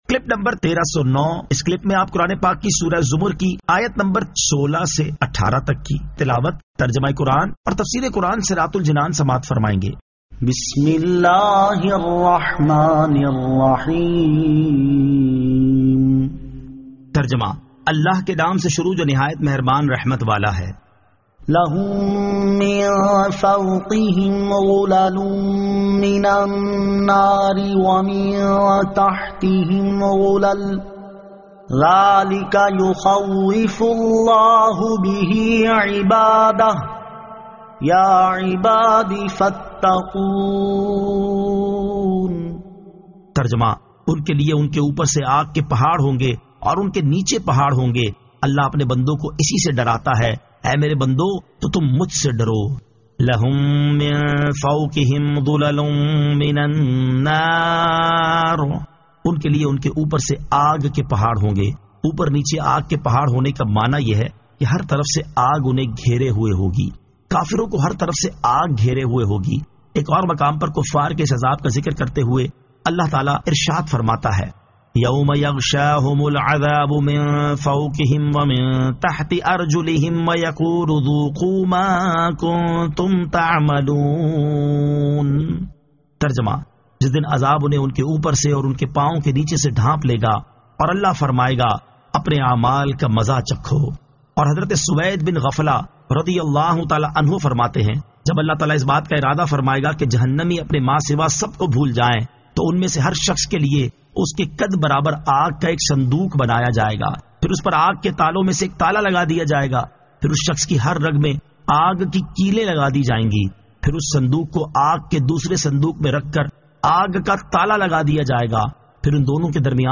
Surah Az-Zamar 16 To 18 Tilawat , Tarjama , Tafseer